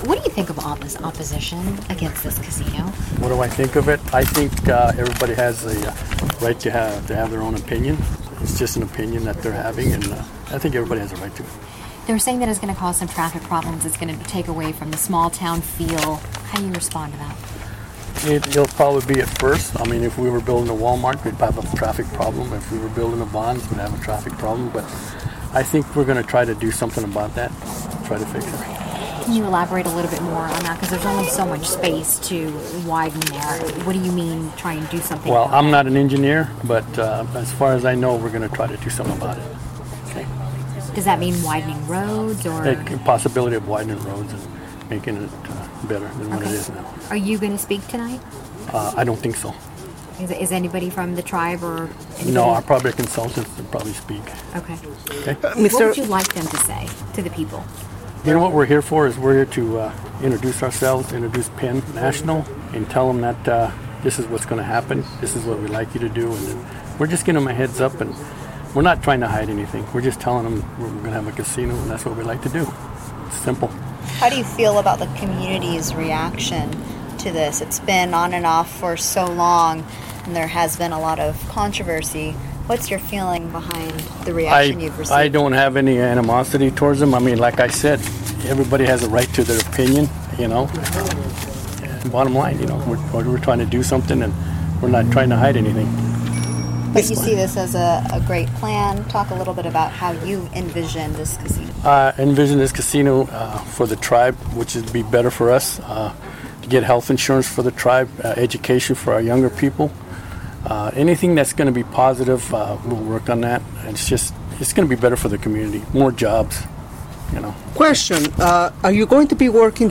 Jamul Tribal Chairman Raymond Hunter, Sr.  spoke with media before the meeting.